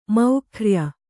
♪ maurkhya